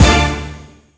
Kick41.wav